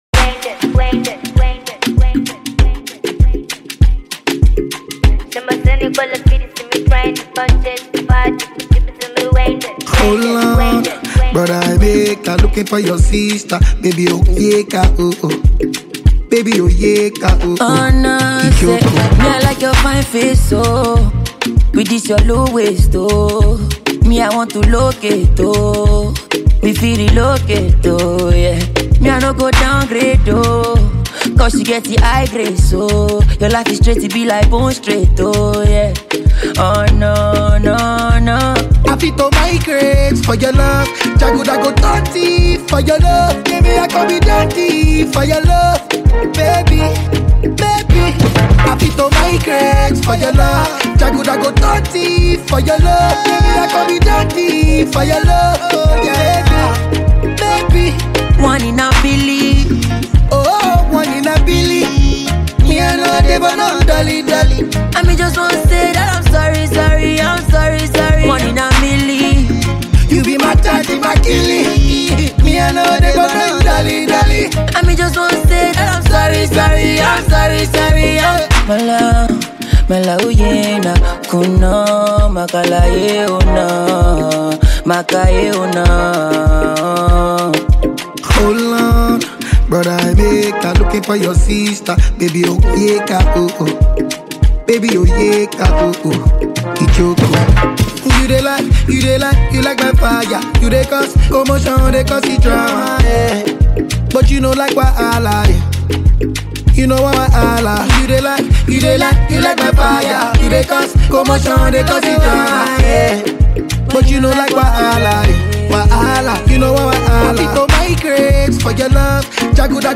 this is a great weekend groove. https